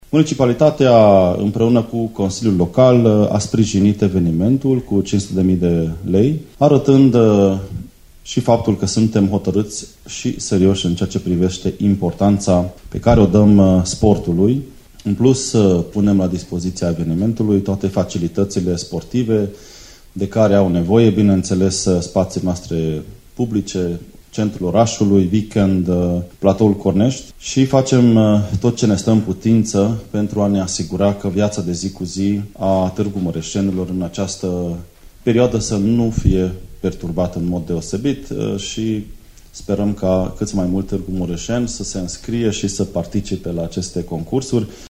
Primarul Municipiului Tg.Mureș, Soós Zoltán: